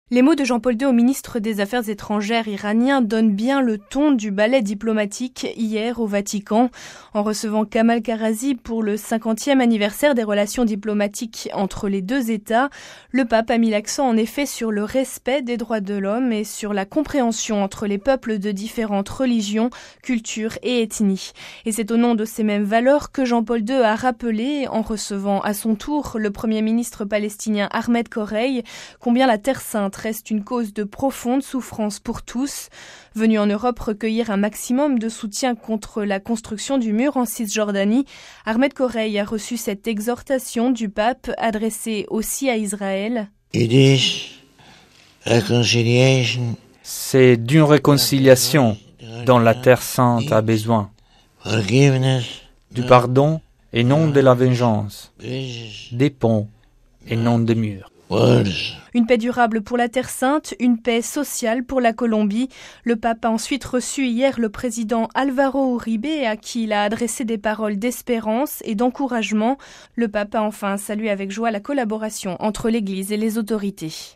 Compte rendu